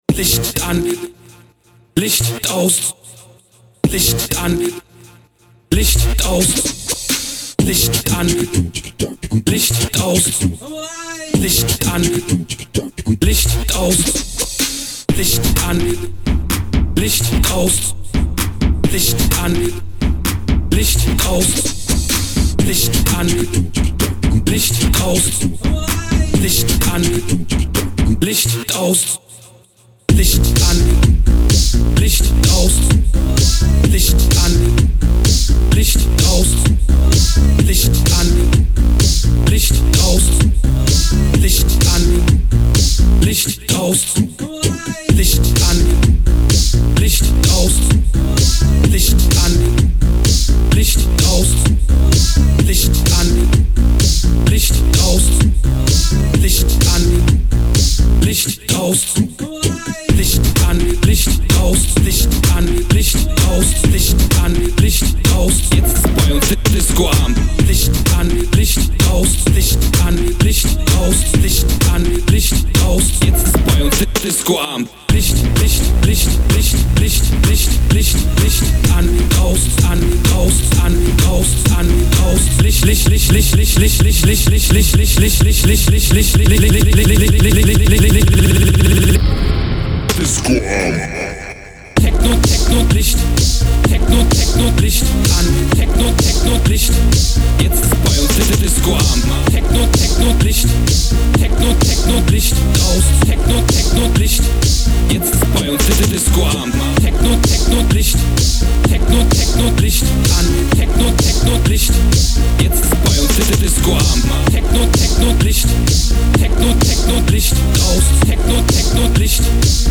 crude stomping synths plus arrogating basses